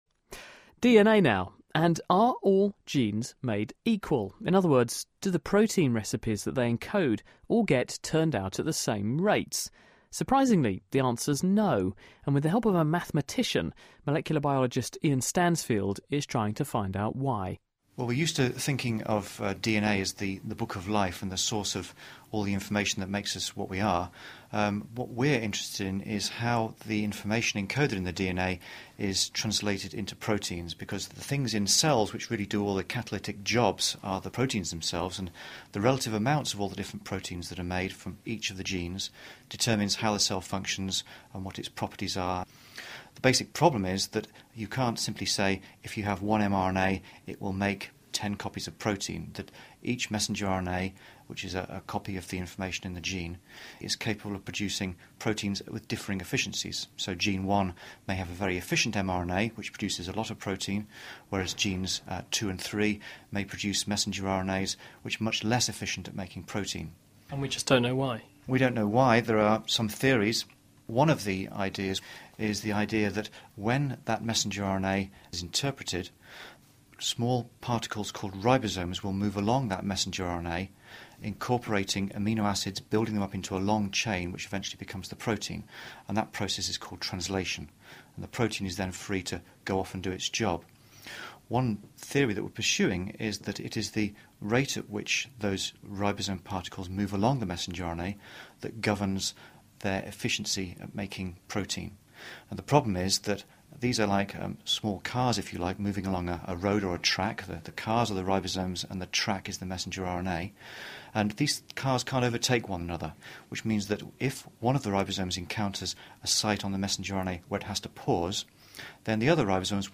Interviews with Scientists
Interviews about medicine, science, technology and engineering with scientists and researchers internationally...